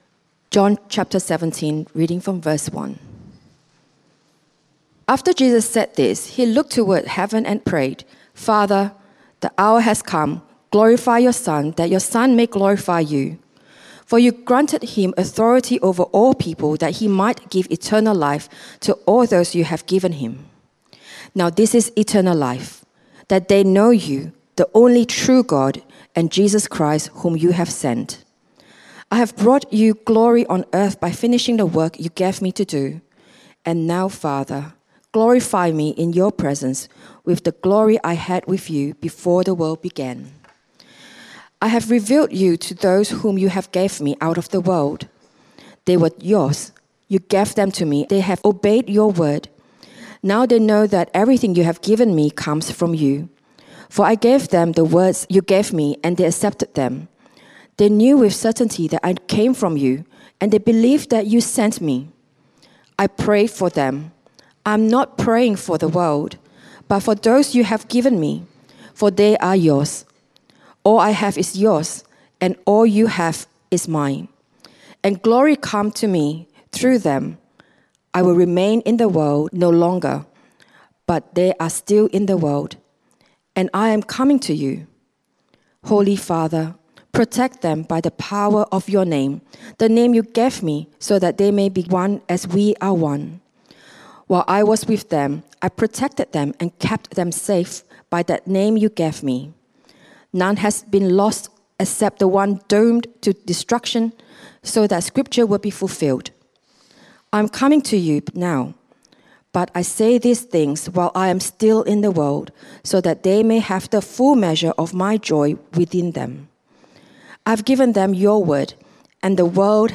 Great Prayers in the Bible Sermon outline